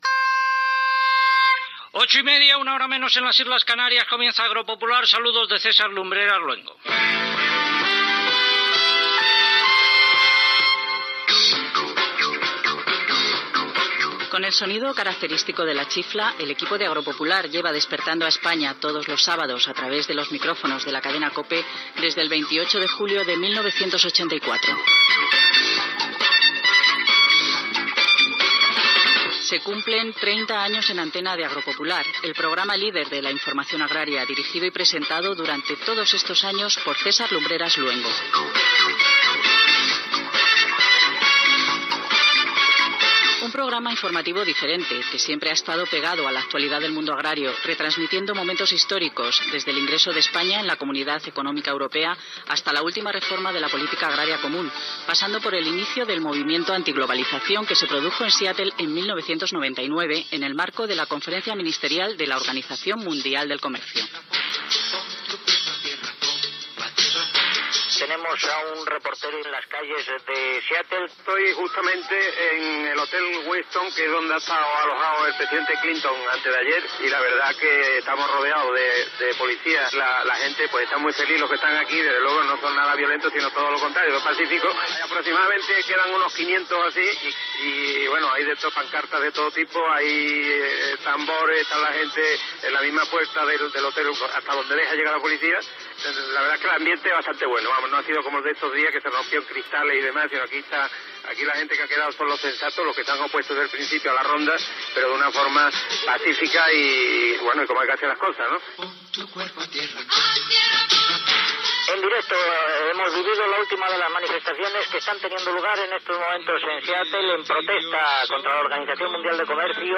Es recullen fragments de diversos programes especials (Austràlia, Antàrtida, Costa Rica, etc.)
Informatiu